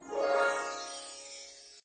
magic_harp_2.ogg